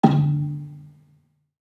Gambang-D2-f.wav